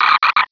Cri de Pifeuil dans Pokémon Rubis et Saphir.